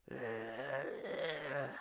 minf_growl.wav